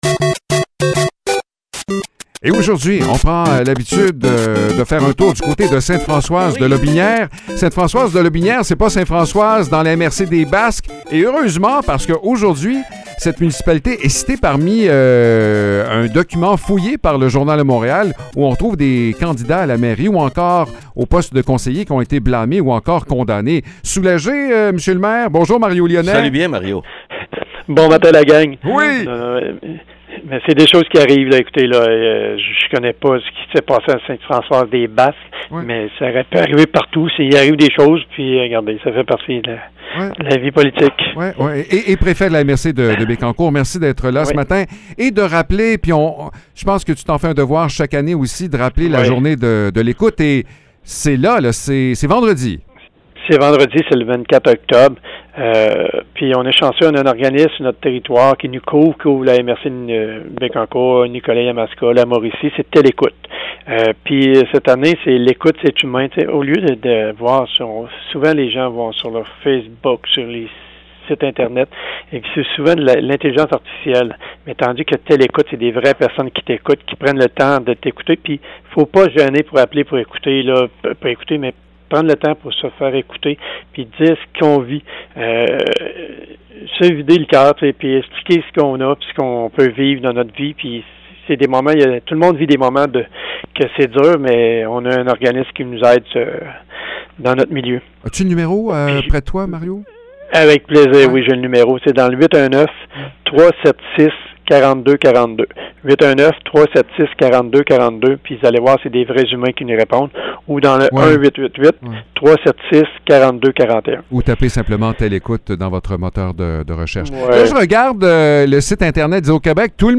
Mario Lyonnais, maire du village de Sainte-Françoise et préfet de la MRC de Bécancour, nous parle d’une journée bien spéciale qui lui tient particulièrement à cœur. Chasseur aguerri, il nous révèle également quel type de chasse sera à l’honneur ce week-end.